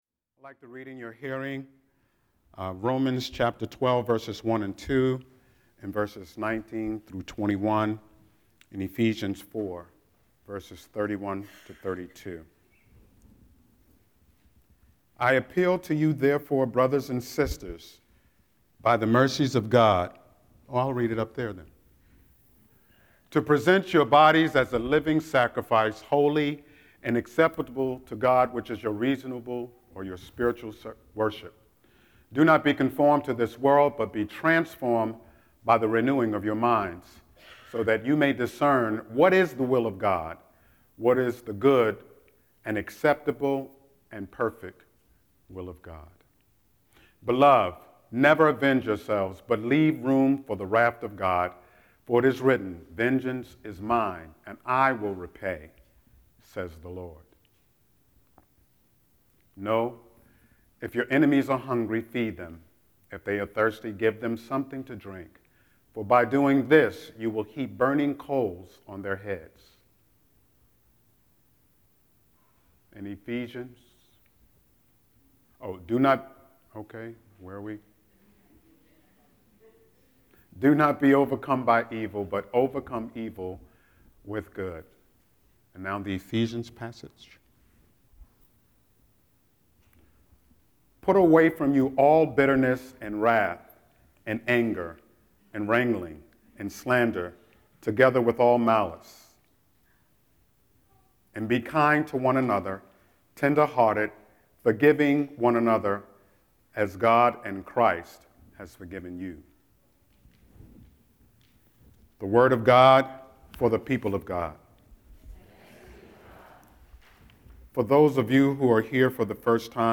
08-09-Scripture-and-Sermon.mp3